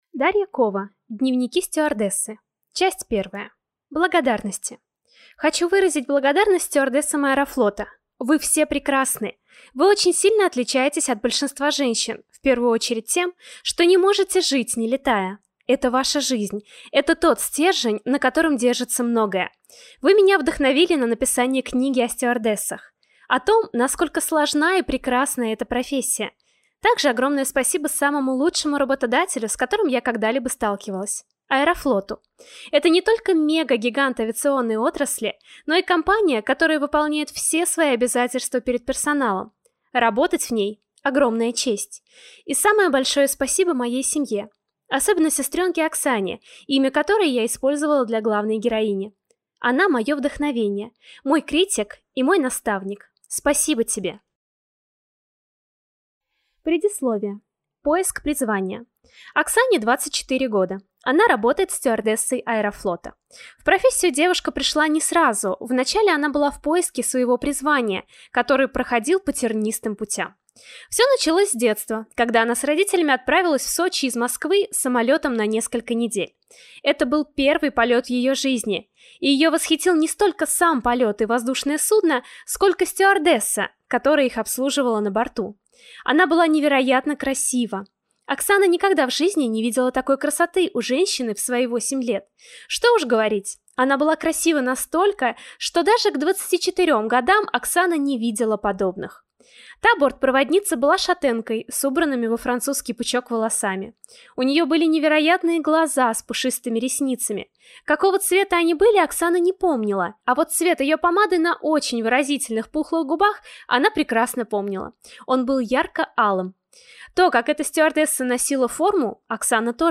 Аудиокнига Дневники стюардессы. Часть 1 | Библиотека аудиокниг